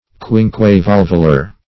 Search Result for " quinquevalvular" : The Collaborative International Dictionary of English v.0.48: Quinquevalve \Quin"que*valve\, Quinquevalvular \Quin`que*val"vu*lar\, a. [Quinque- + valve, valvular: cf. F. quinqu['e]valve.]